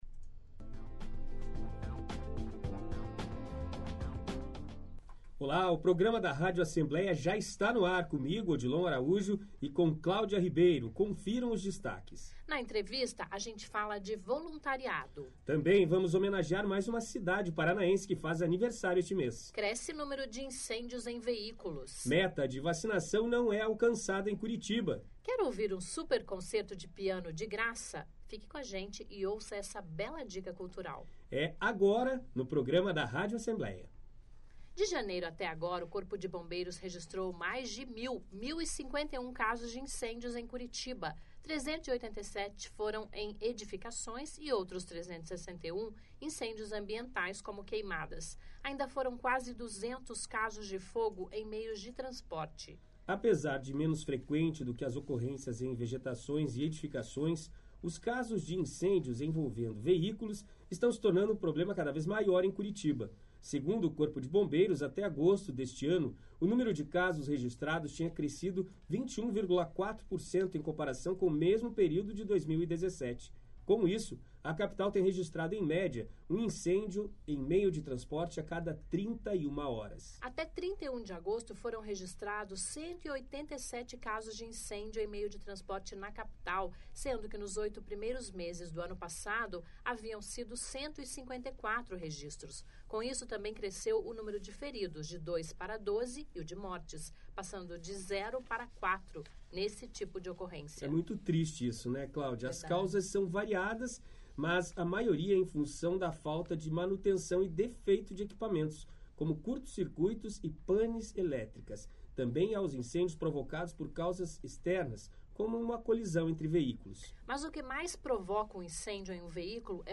- Na entrevista, a gente fala de voluntariado;